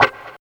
134 GTR 3 -L.wav